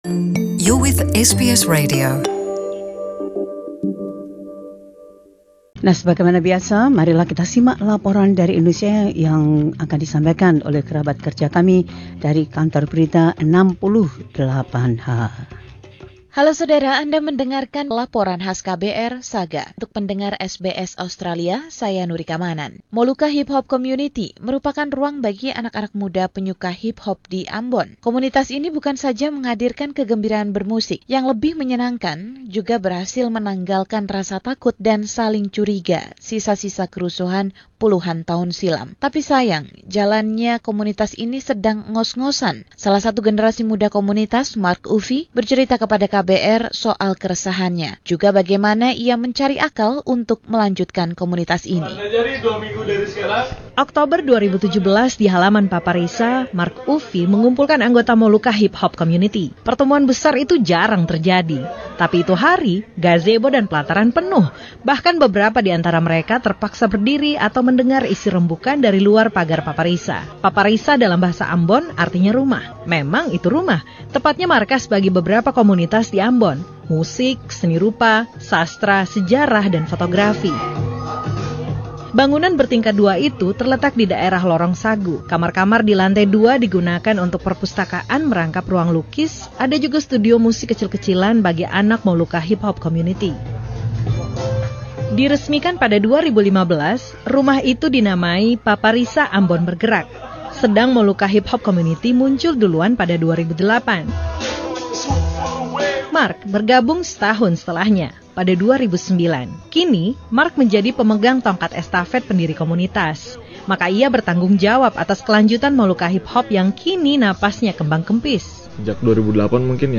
Laporan KBR 68H: Komunitas Molukka HipHop